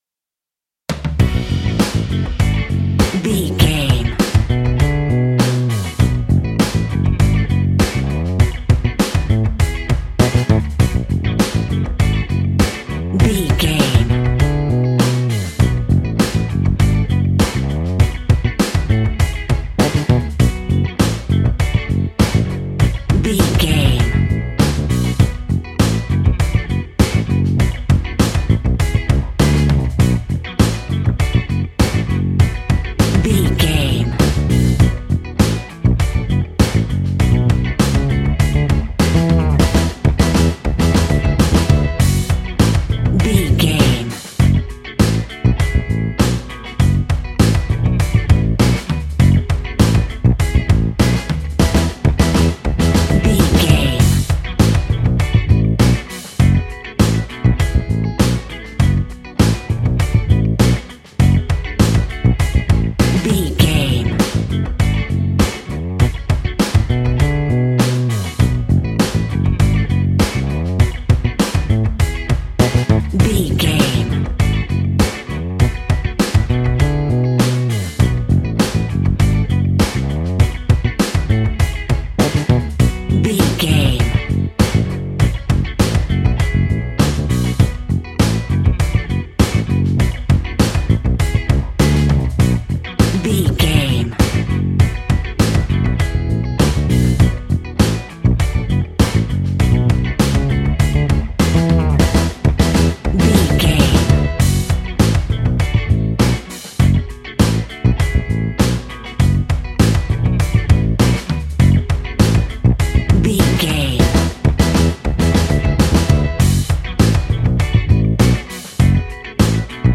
Aeolian/Minor
G♭
groovy
funky
lively
electric guitar
electric organ
drums
bass guitar
saxophone
percussion